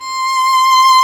Index of /90_sSampleCDs/Roland L-CD702/VOL-1/STR_Violin 2&3vb/STR_Vln3 _ marc
STR VLN3 C 5.wav